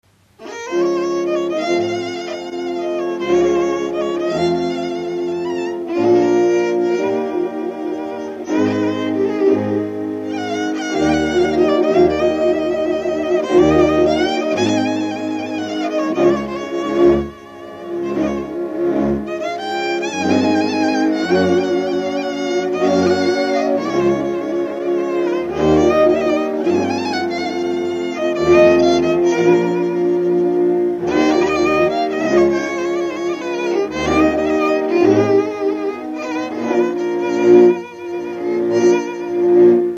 Dallampélda: Hangszeres felvétel
Erdély - Kolozs vm. - Magyarpalatka
hegedű
brácsa
bőgő
Műfaj: Lassú cigánytánc
Stílus: 3. Pszalmodizáló stílusú dallamok